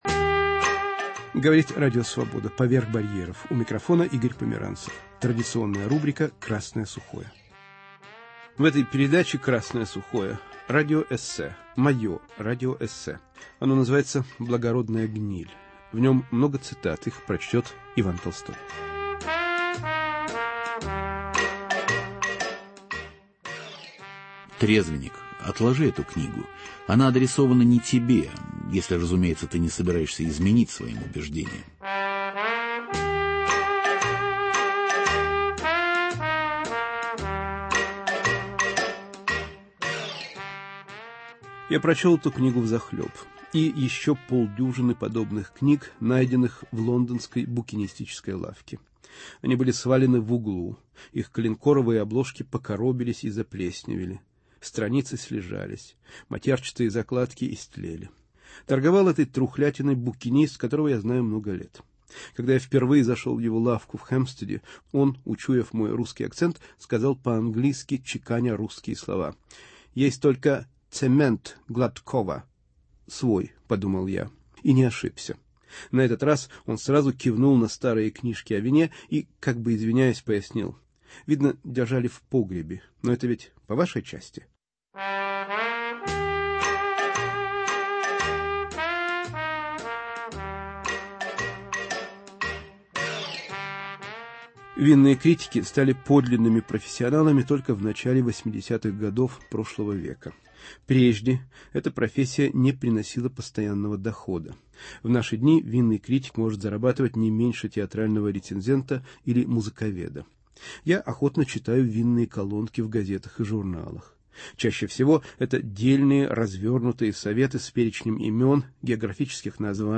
В рубрике "Красное сухое" радиоэссе о том, что и как писали винные критики в XIX и в начале XX века.